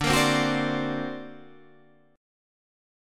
D+7 chord